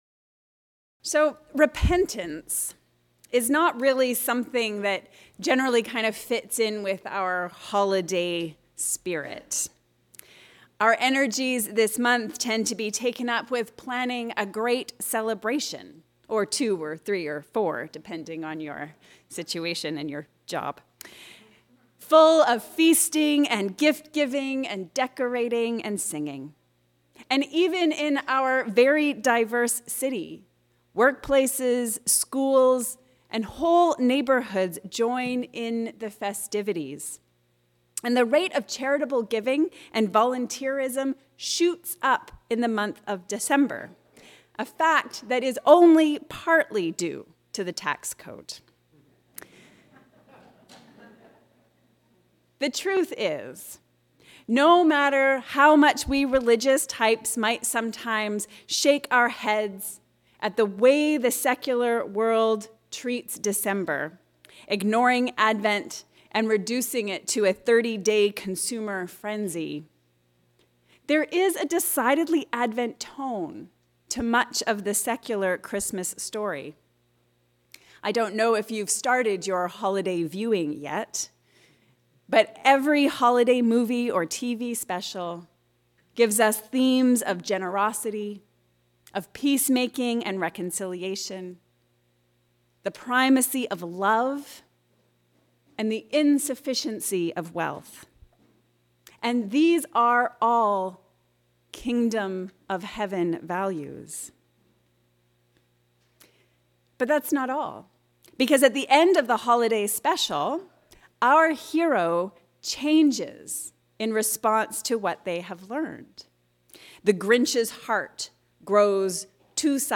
Repentance – Advent style. A sermon on Matthew 3:1-12